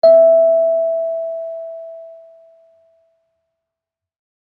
kalimba1_circleskin-E4-ff.wav